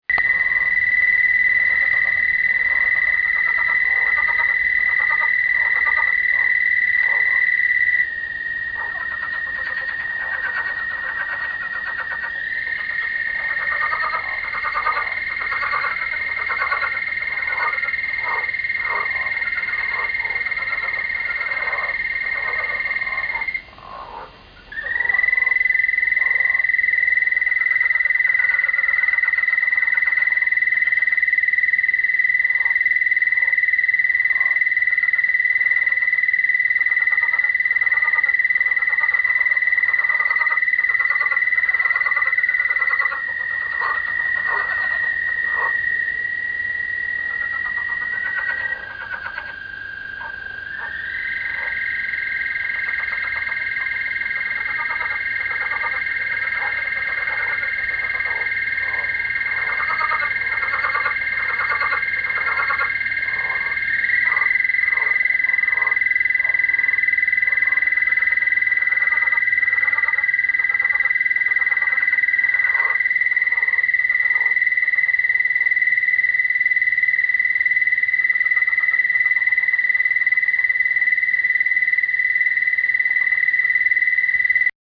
The SLFs make a variety of sounds, so I suspect you are hearing their lower-pitched balloon call and the higher chuckling call.
I took my little point ‘n shoot digital camera outside last evening, not to take a movie but to make a sound recording. The most amazing noises were coming out of my goldfish pond.
If you listen to this clip, you’ll hear American toads (the high pitched trill), Southern leopard frogs (the deep, rubbery sound that sounds like someone constructing balloon animals at a kid’s party) and something else, which I hope someone out there can identify for me.
Frog Songs in Oklahoma
Now that is what you call an inspiring chorus.
frogsongs.mp3